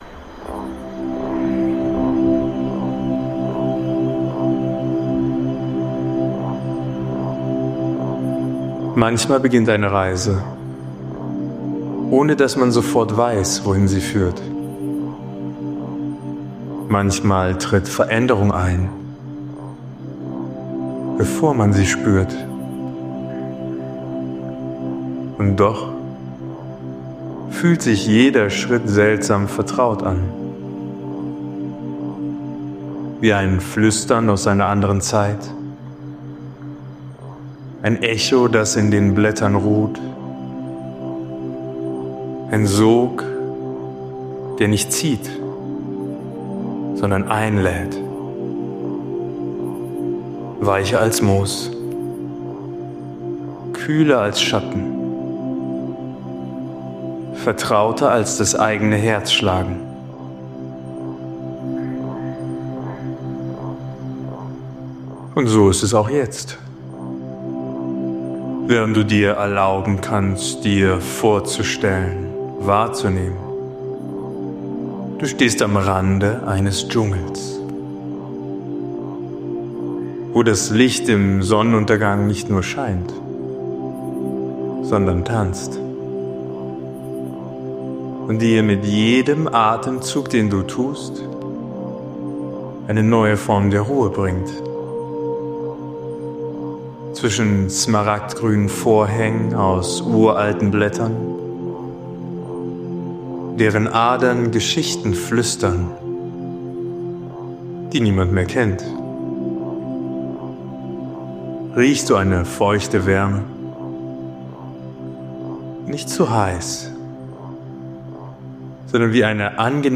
Da die Kapitel bei manchen Podcastanbietern leider durcheinandergeraten sind, habe ich dir hier nun das gesamte Hörbuch zusammengestellt.